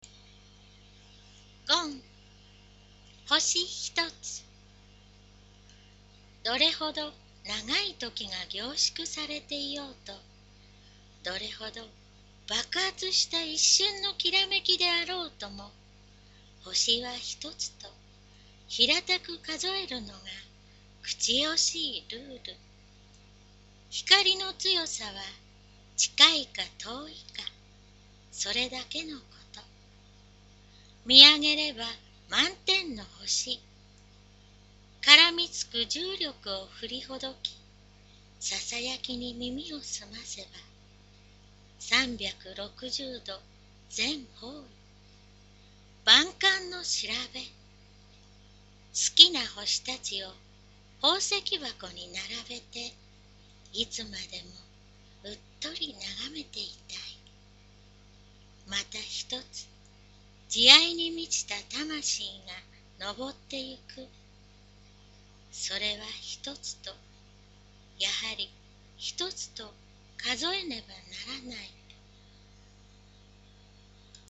形式…口語自由詩
poemreadhoshihito001.mp3